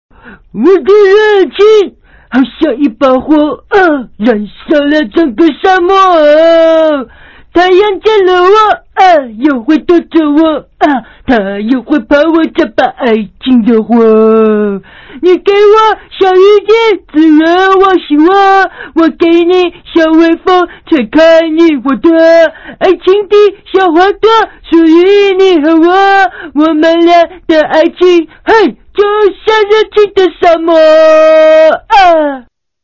慢摇舞曲